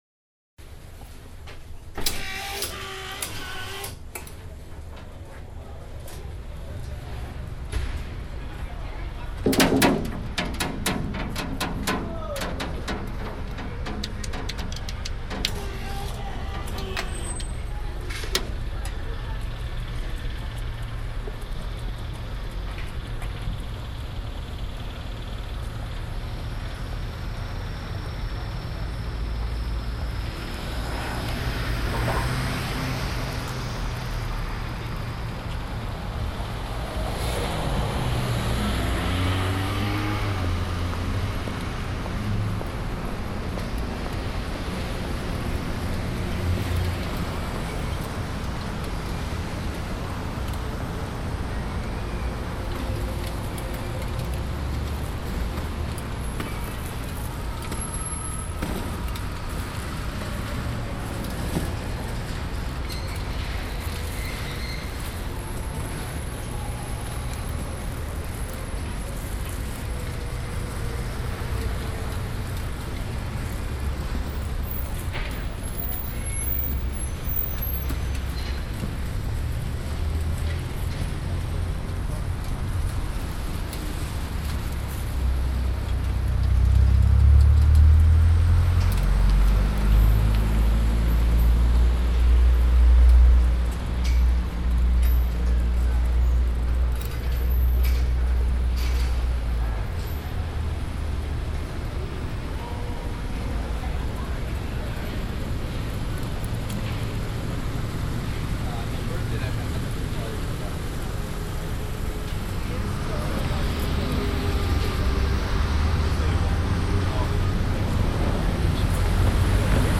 these six recording were rendered from my first day win in-ear binaural microphones.
no processing was done to the sound, however in cases there are edits.
field_02 walking through the streets of manhattan from the west side, to my home in nolita where i picked up my dog, then across housten and over to the east village.
this file has a number of edits as i seemed to recall the walk out of order in retrospect.